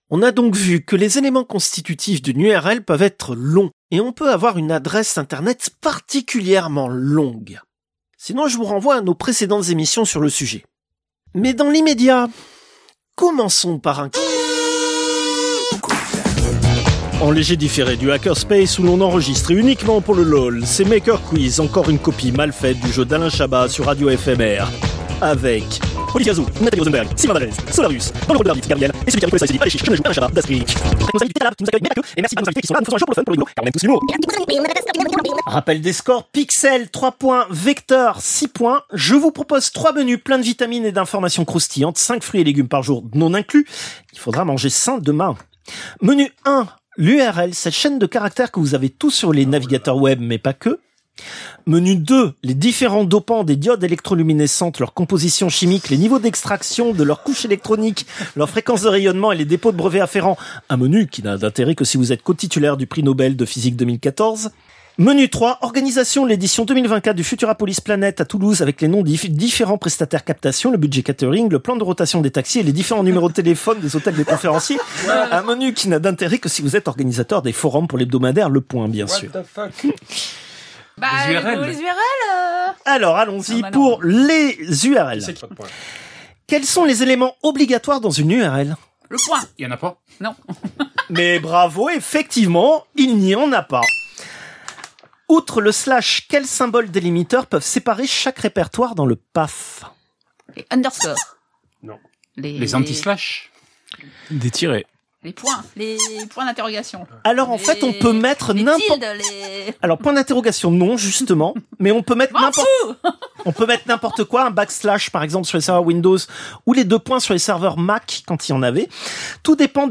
Extrait de l'émission CPU release Ex0226 : L'adresse était trop longue.
] commençons par un quiz : [Musique d'un générique de jeu télévisé] En léger différé du hackerspace où l'on enregistre, et uniquement pour le lol, c'est MakerQuiz.
[bip, l'animateur de jeu présente, le son est en accéléré] Bonjour et bienvenue à tous.